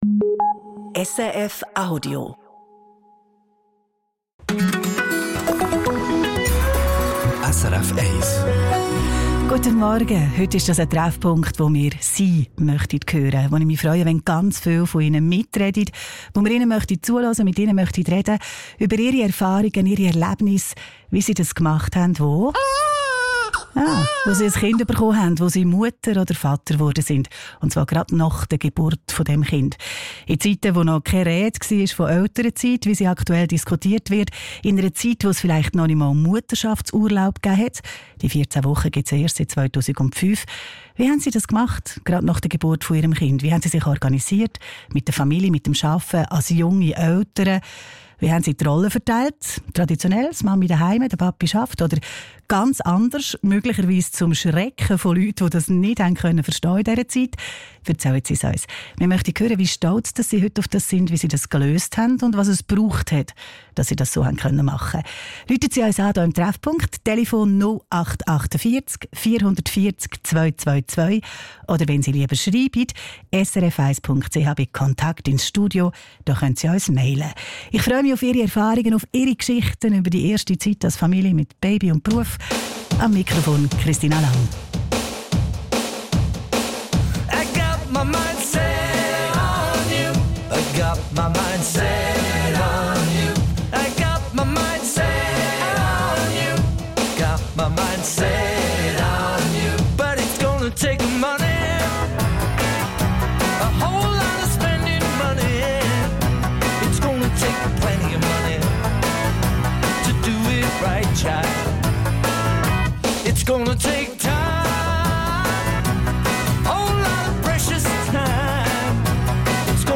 In der Sendung «Treffpunkt» sollen Hörerinnen und Hörer zu Wort kommen und von ihren Erlebnissen erzählen: Wie haben sie diese Zeit gemeistert?